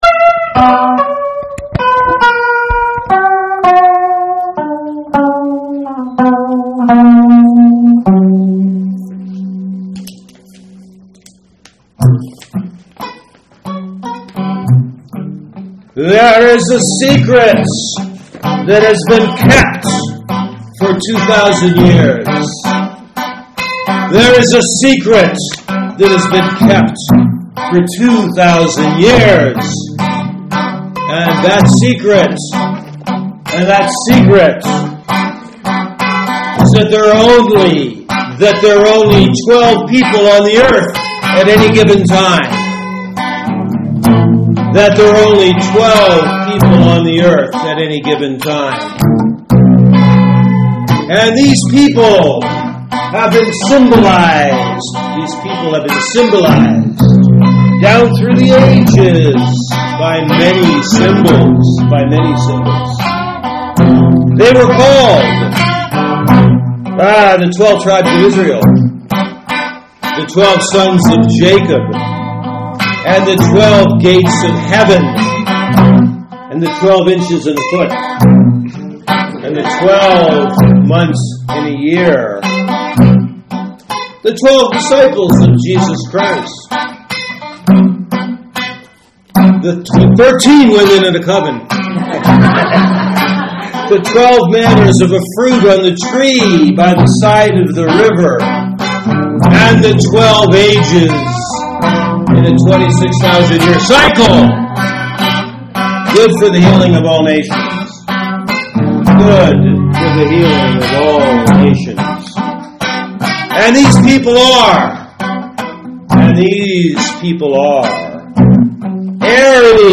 The Zodiac Song - Performed Live!
Lead Vocal,
Backup Vocal
Guitar